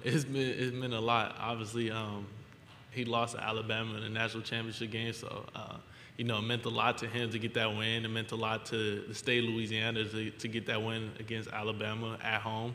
Louisiana State starting quarterback Jayden Daniels spoke Monday at the first day of SEC Media Days.